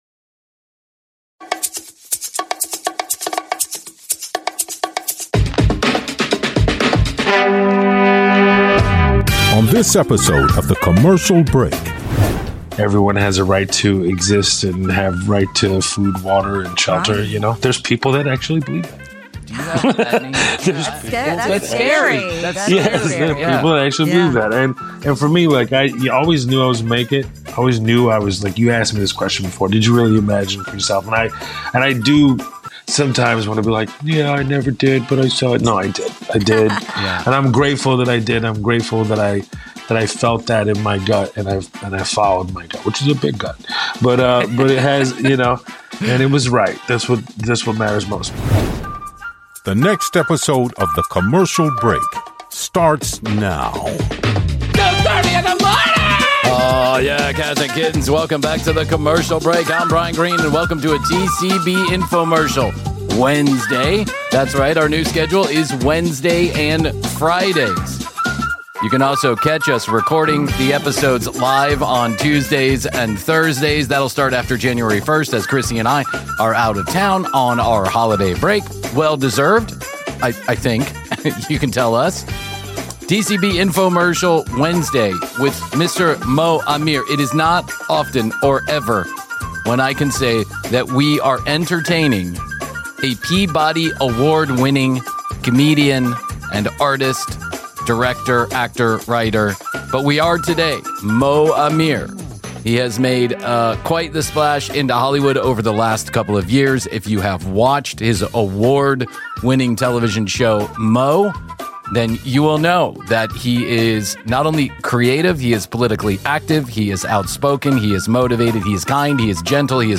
Click to listen, free! 929 episodes in the Improv genre.
On this TCB Infomercial with comedian Mo Amer in a hilarious, sharp, and surprisingly heartfelt conversation about stand-up, culture, identity, and turning real life into comedy.